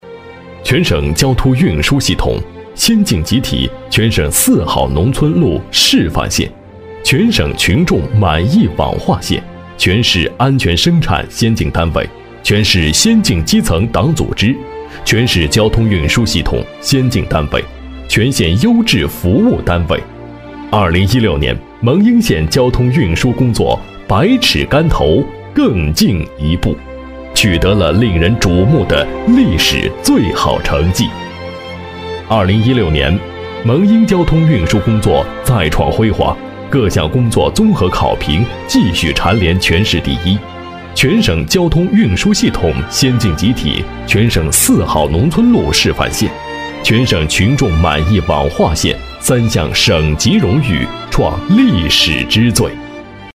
自然诉说 企业专题,人物专题,医疗专题,学校专题,产品解说,警示教育,规划总结配音
大气震撼男音，成熟稳重。擅长专题党建，纪录片，宣传片等。